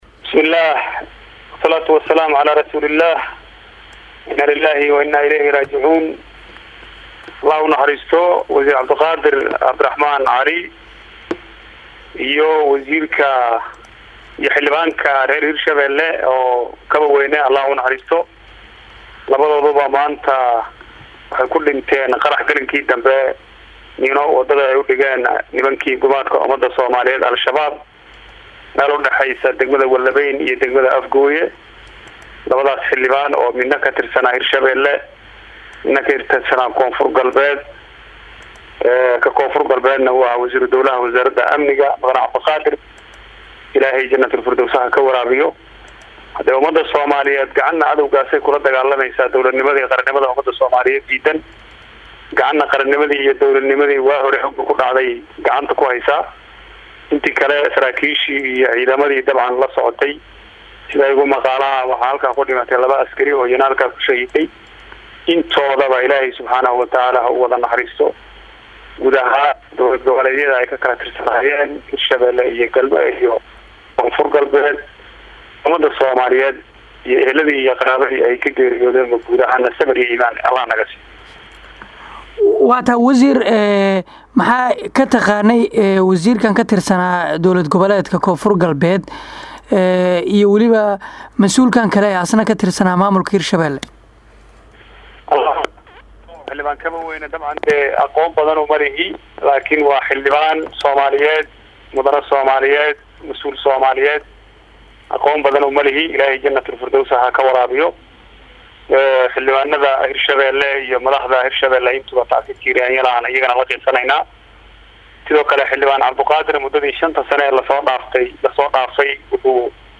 Dhageyso Wareysiga Wasiirka Koonfurgalbeed ee Soomaaliya
Waerysi-Wasiirka-Warfaafinta-Koonfur-Galbeed-Ugaas-Xasan-.mp3